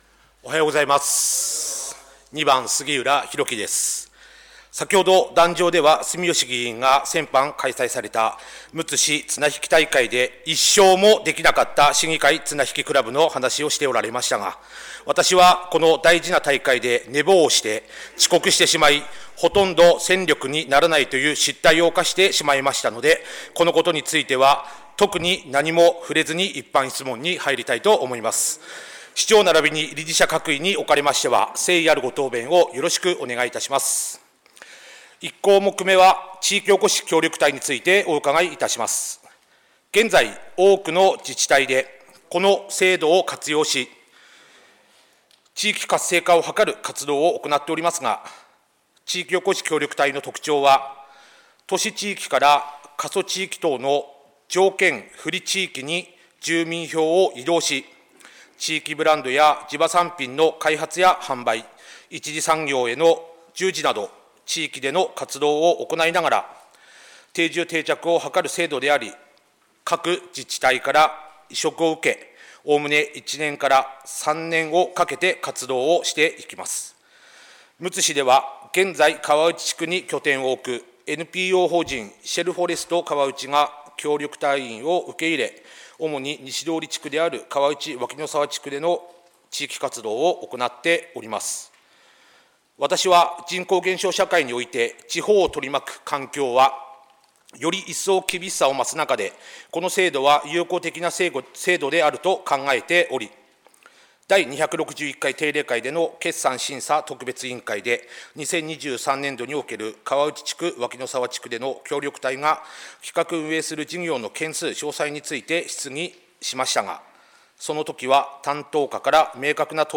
むつ市議会では、本会議のようすを多くの皆さんに聴いていただくために、音声データを掲載しています。